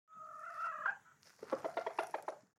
دانلود صدای مرغ 1 از ساعد نیوز با لینک مستقیم و کیفیت بالا
جلوه های صوتی
برچسب: دانلود آهنگ های افکت صوتی انسان و موجودات زنده دانلود آلبوم صدای انواع مرغ از افکت صوتی انسان و موجودات زنده